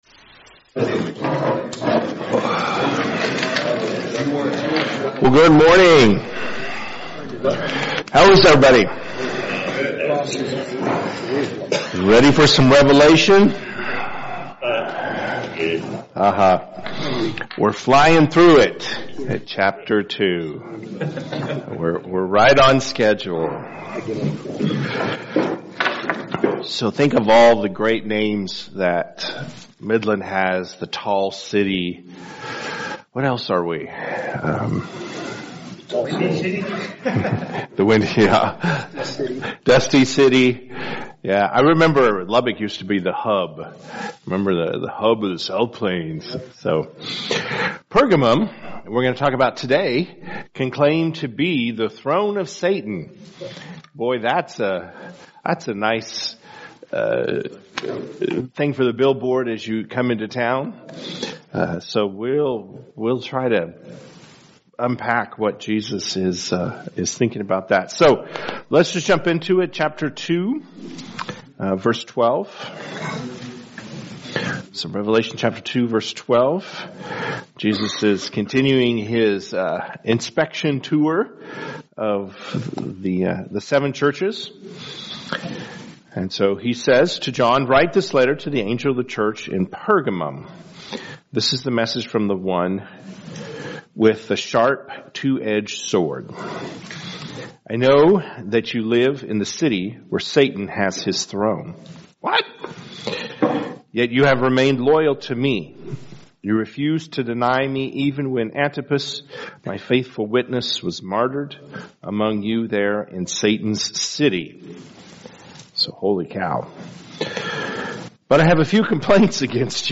Men’s Breakfast Bible Study 3/5/24
Mens-Breakfast-Bible-Study-3_5.mp3